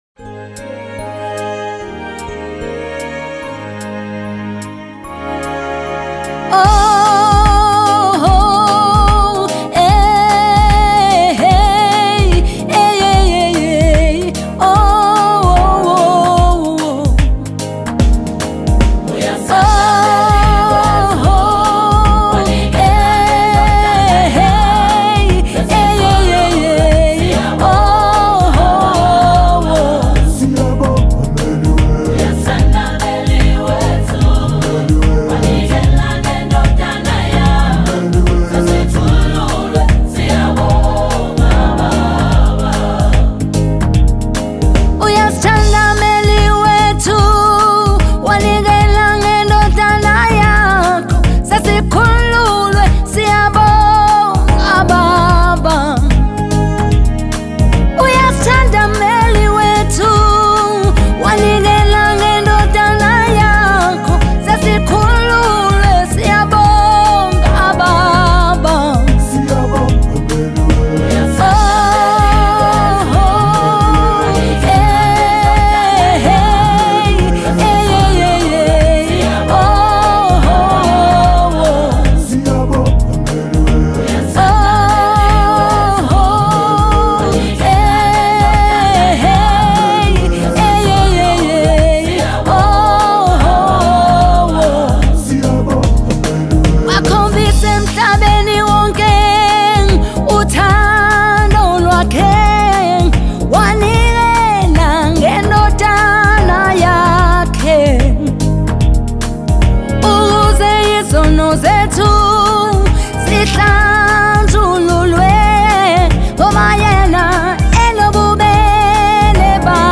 January 30, 2025 Publisher 01 Gospel 0